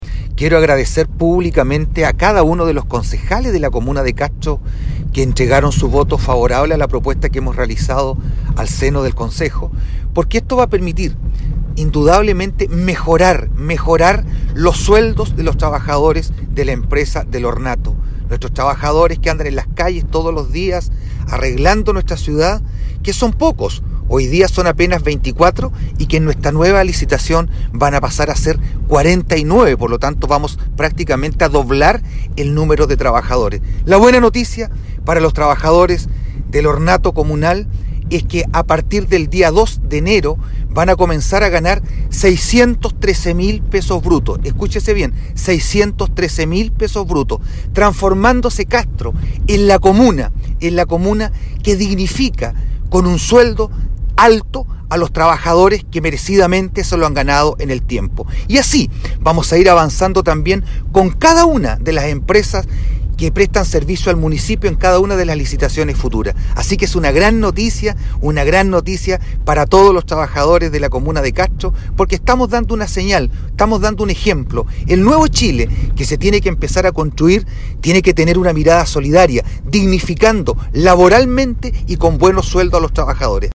CUÑA-ALCALDE-VERA-TRABAJADORES-DEL-ASEO.mp3